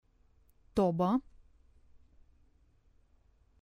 tobă